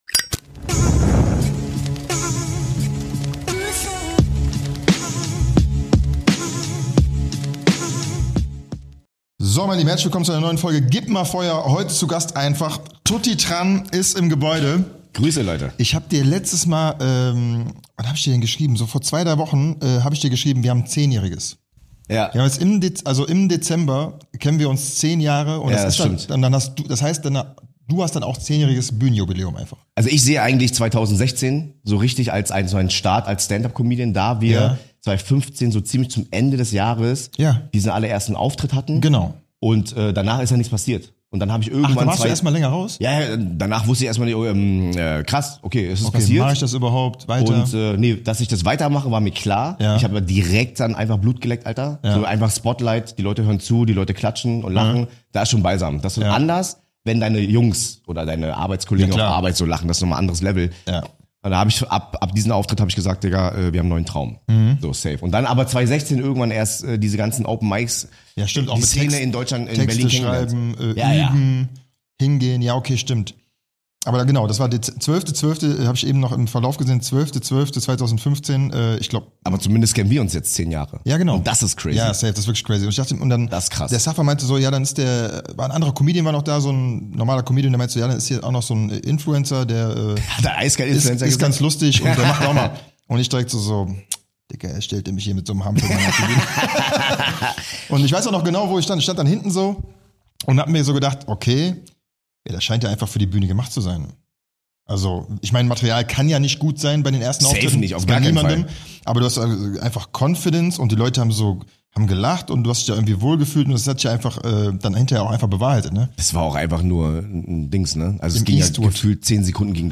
Tutty Tran war bei uns zu Gast und es war ein geiler Talk mit emotionalen Höhen und Tiefen, viel Gelächter, viel Mitgefühl und vor allem ganz viel diebischen Spaß.